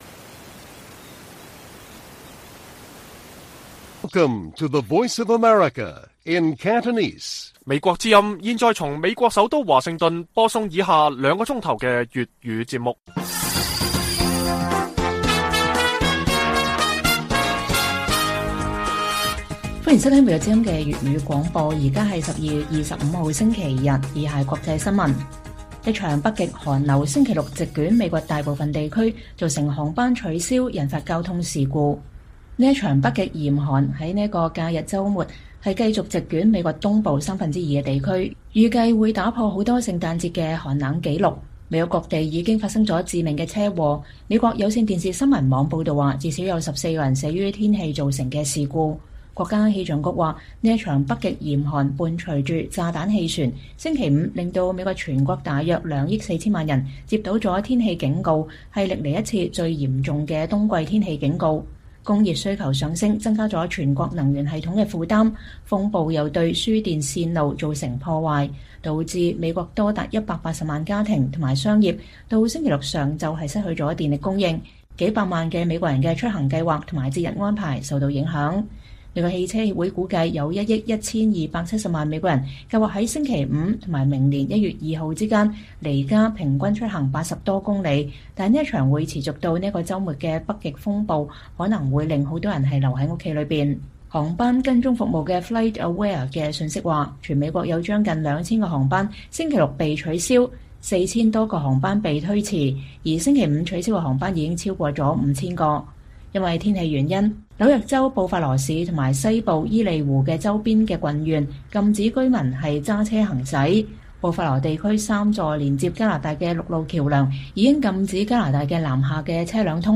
粵語新聞 晚上9-10點: 美國極度嚴寒造成航班取消、引發交通事故